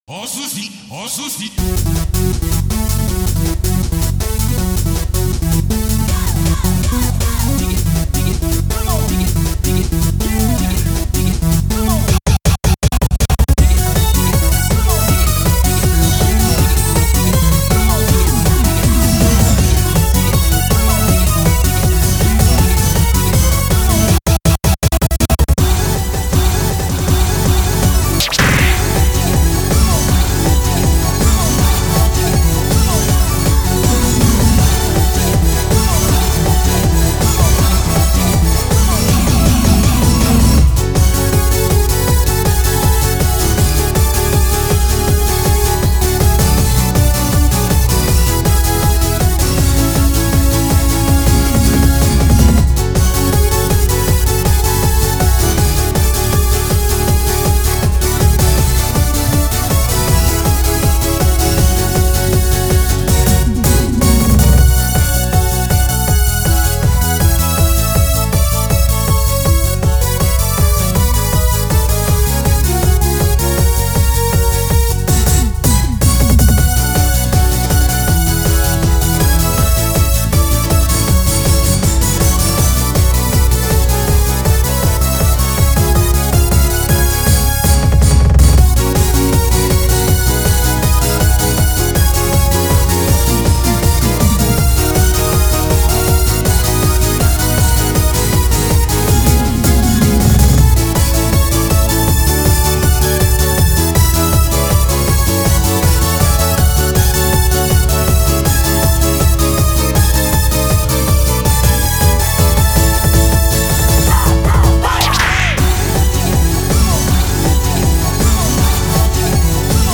BPM160
Audio QualityPerfect (High Quality)
rave (?) remix